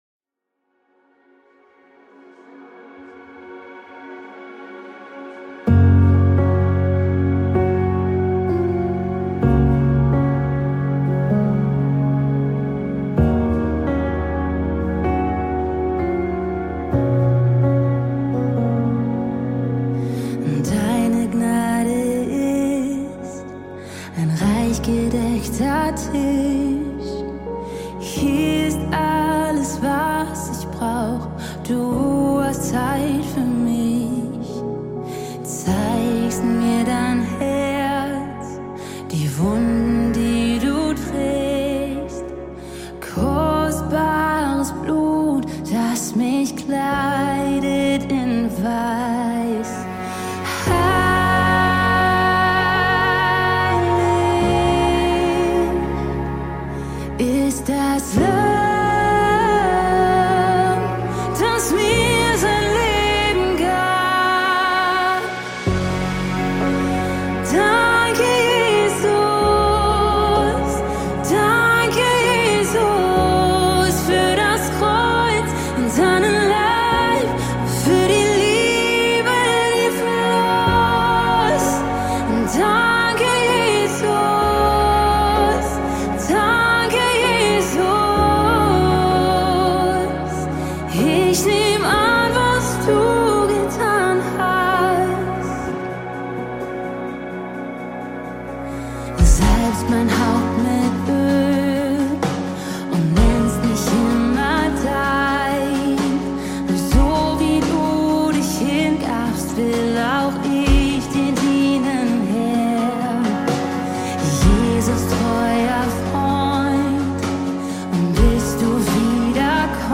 273 просмотра 135 прослушиваний 1 скачиваний BPM: 64